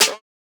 take care snare ~.wav